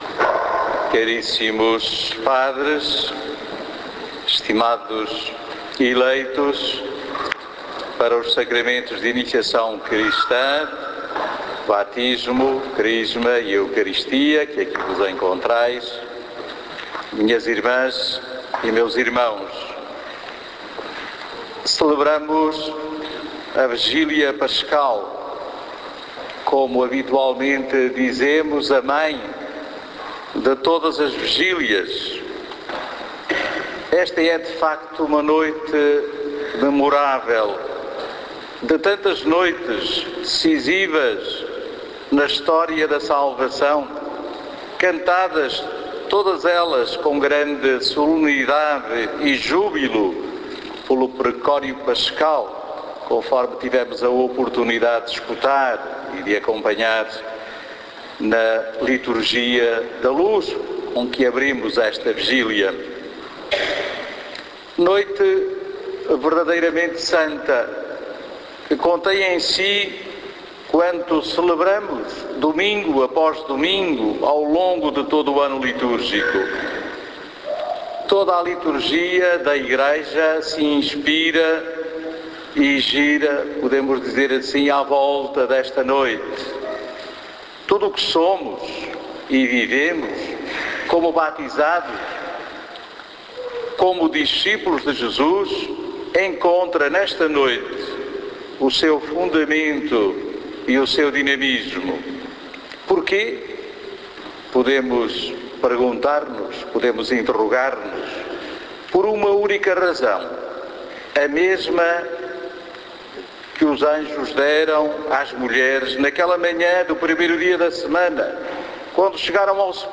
Homilia_vigilia_pascal_2014.mp3